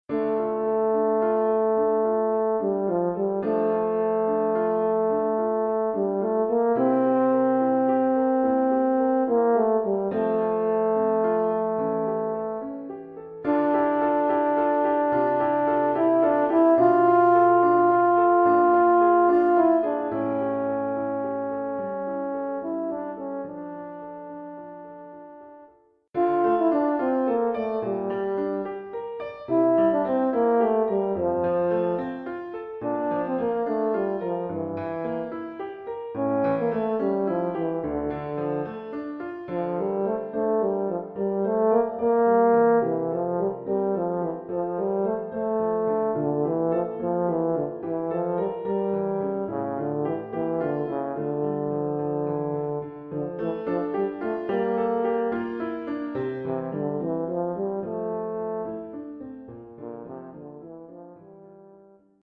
(fa ou mib) et piano.
Niveau : élémentaire.